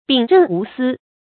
秉正无私 bǐng zhèng wú sī
秉正无私发音
成语注音 ㄅㄧㄥˇ ㄓㄥˋ ㄨˊ ㄙㄧ